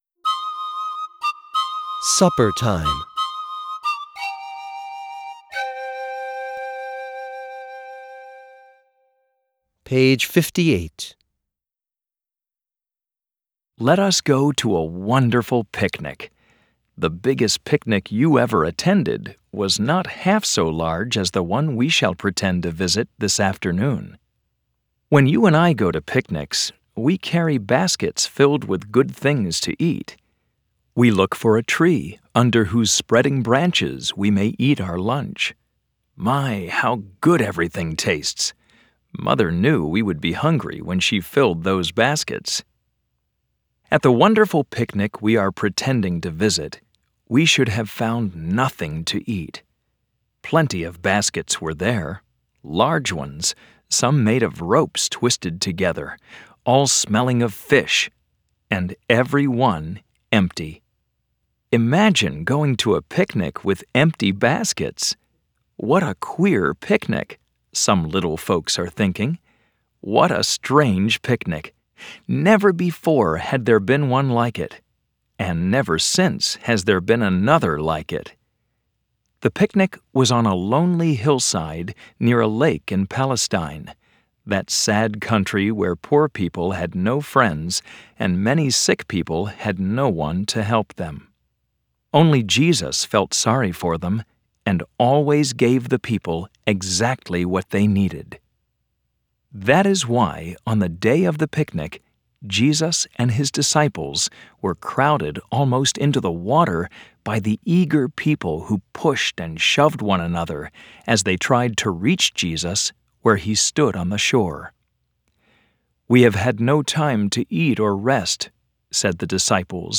Dover Read & Listen sets are filled with some of the most timeless tales ever written for children. Plus, each beautiful keepsake edition includes an audio CD of the very best stories from the book.